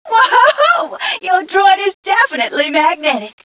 1 channel
mission_voice_tgca054.wav